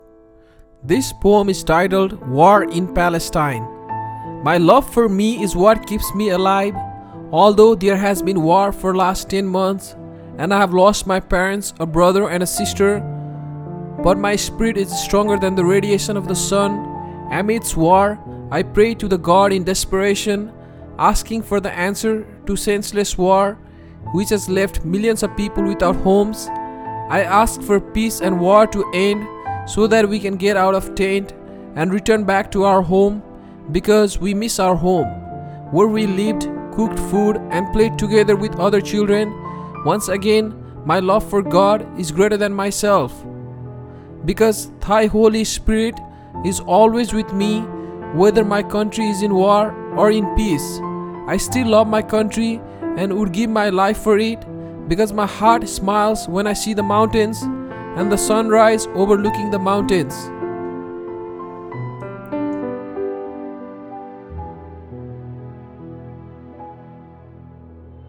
by | Aug 25, 2024 | Poem | 0 comments